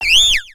Cri de Trompignon dans Pokémon X et Y.